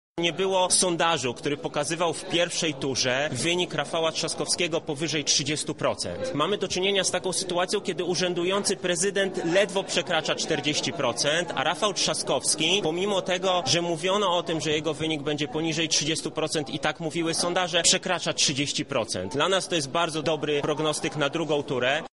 Wieczór wyborczy w Radiu Centrum. Relacja z lubelskich sztabów
Jesteśmy zadowoleni z wyniku Rafała Trzaskowskiego – mówi poseł Michał Krawczyk